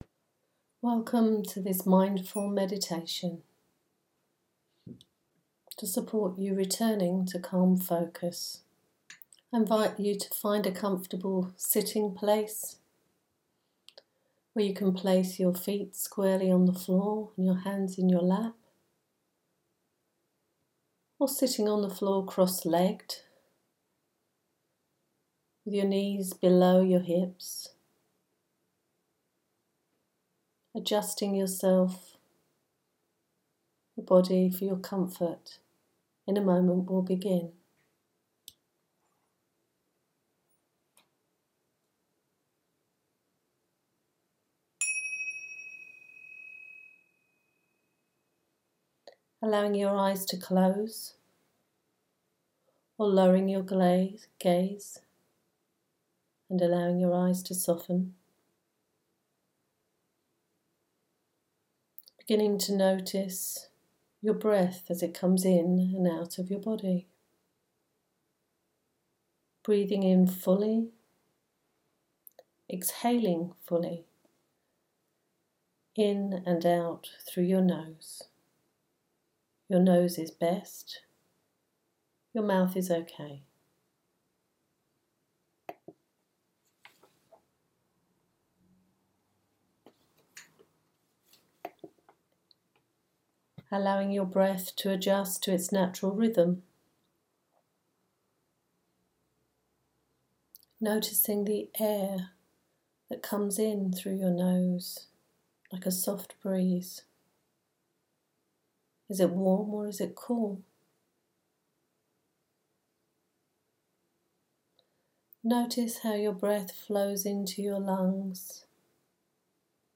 Spotify Embed: An Interview with Marshall Rosenberg 2015